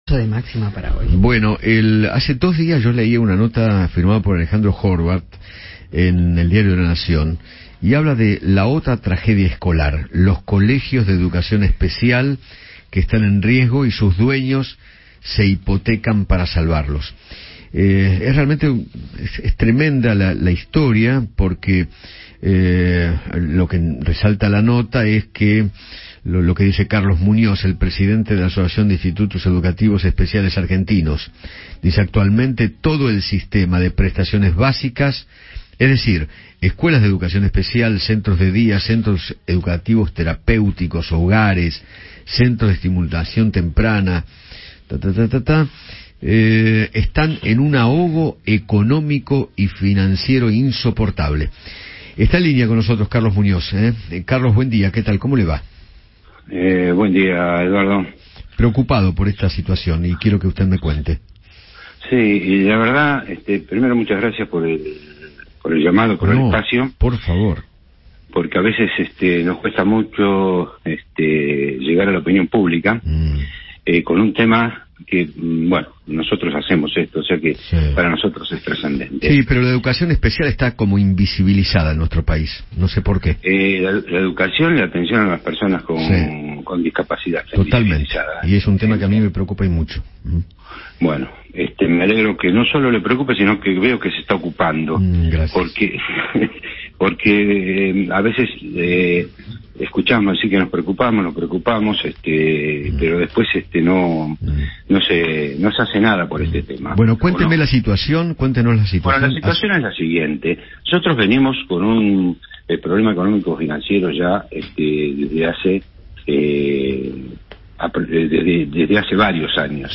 dialogó con Eduardo Feinmann acerca de la crisis económica que están viviendo las instituciones de educación especial en el país.